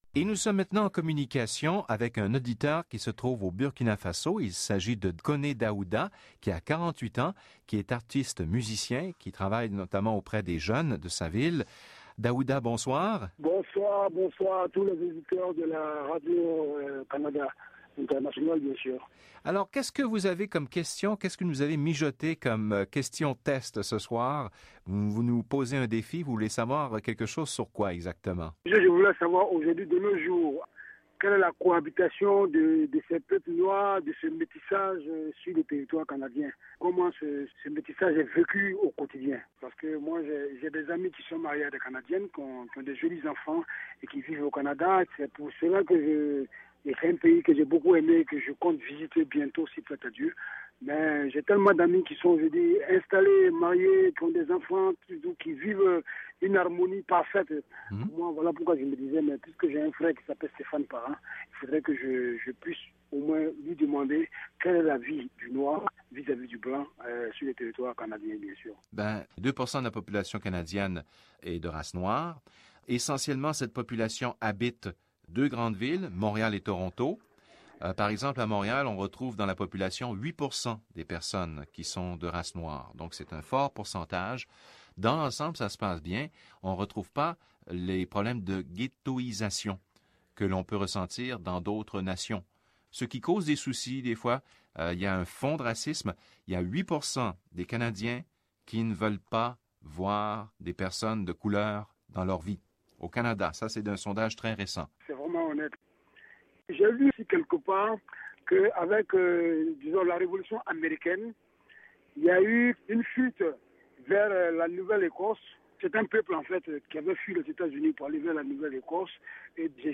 animateur